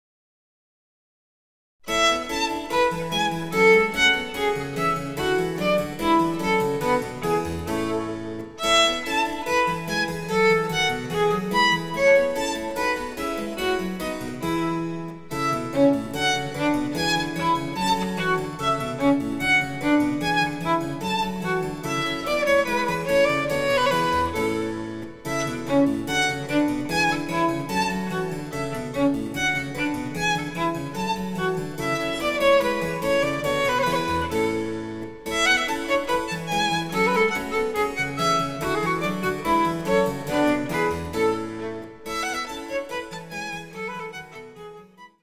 ■ヴァイオリンによる演奏（イ長調）
電子チェンバロ